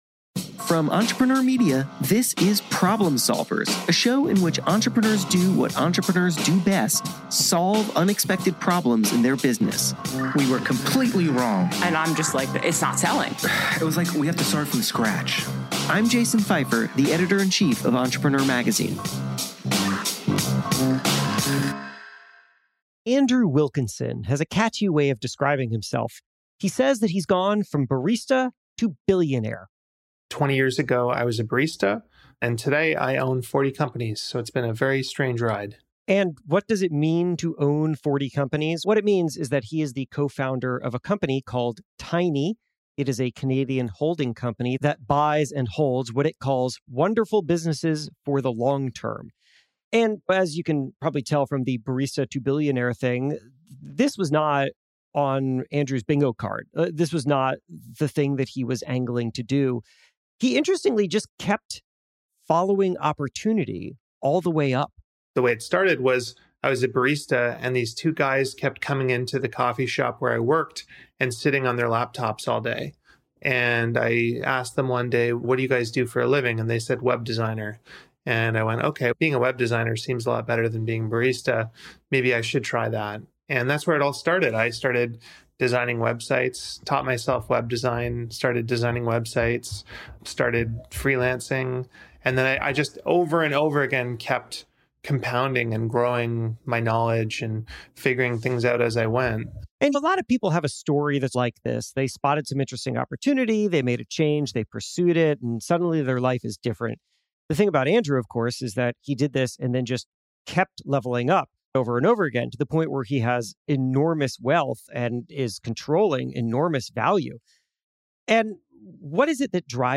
In this deep and personal conversation, we explore what it means to constantly want more, and how to find satisfaction in your accomplishments.